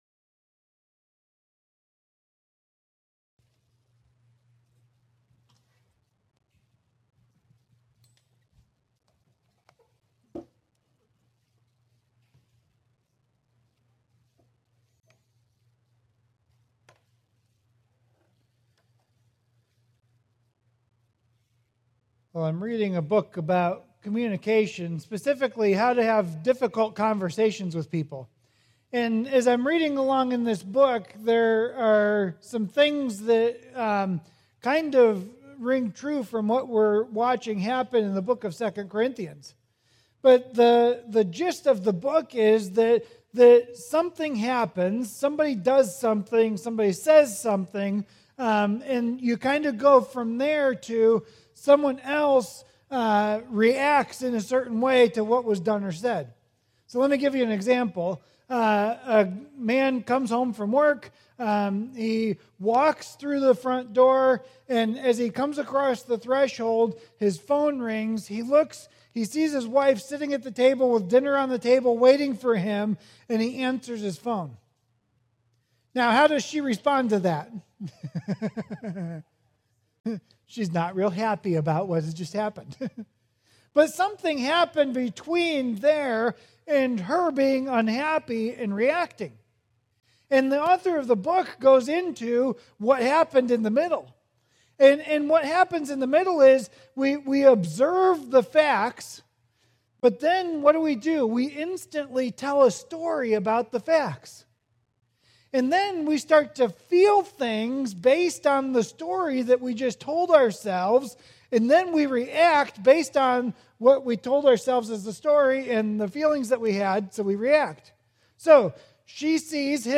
Sermons | Grace Fellowship Church